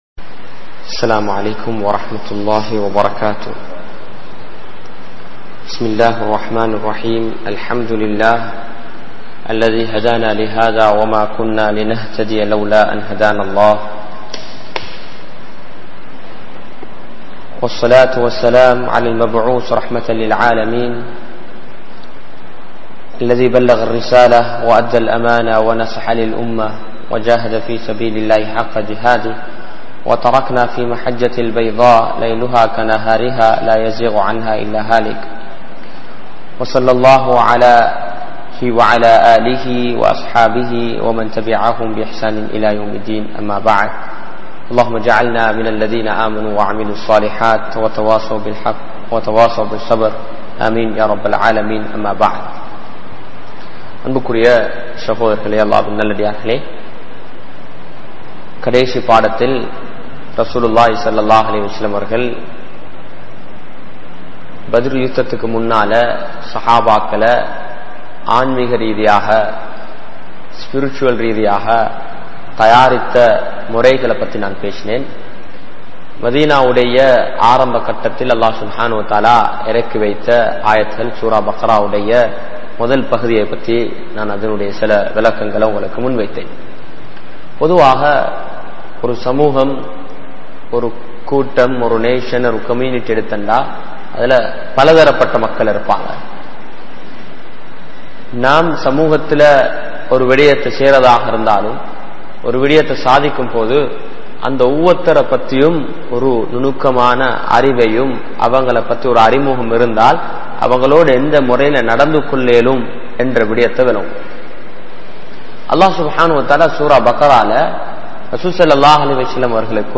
Sirantha Manitharhalaaha Maarungal(சிறந்த மனிதர்களாக மாறுங்கள்) | Audio Bayans | All Ceylon Muslim Youth Community | Addalaichenai